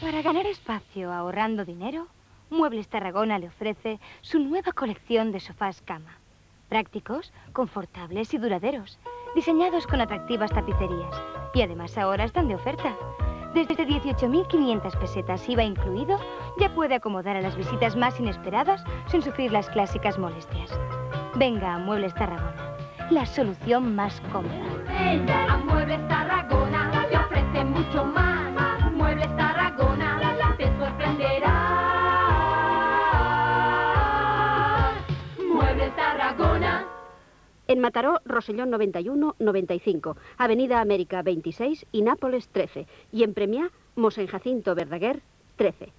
Gènere radiofònic Publicitat